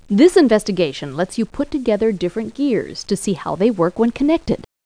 1 channel
00307_Sound_invest.gears.mp3